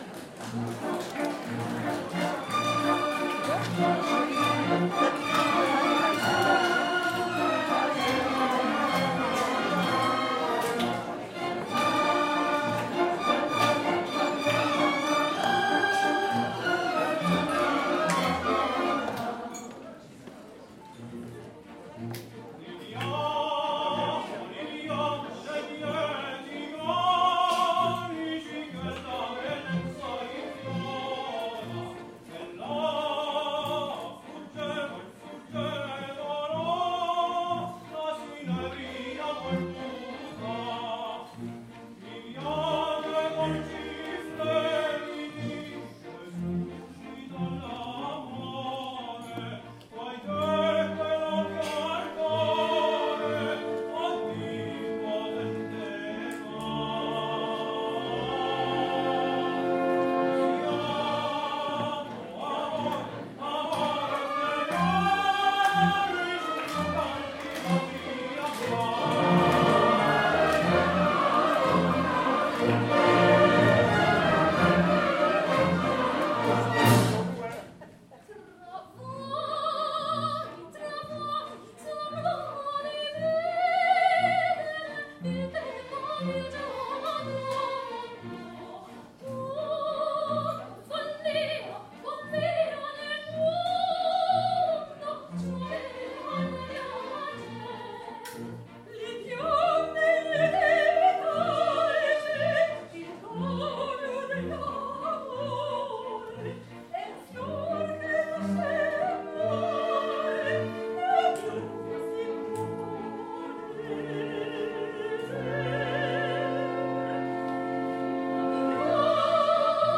Les applaudissements de rappel des spectateurs ont comblé les organisateurs. Le bis réclamé et la magie de l’art lyrique ont inondé l’espace lorsque, flûte de champagne levée, tous les artistes ont interprété le Brindisi (Libiamo ne’ lieti calici), l’air célèbre de La Traviata de Giuseppe Verdi  : les spectateurs du Tram n’ont pu s’empêcher d’entonner eux aussi ce Libiamo !